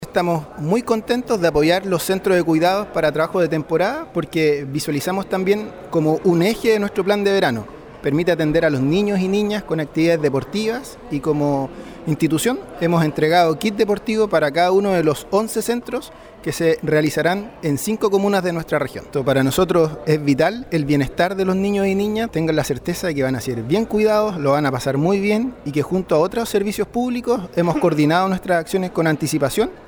También Jonathan Pino Naranjo Director del Instituto Nacional de Deportes, agregó: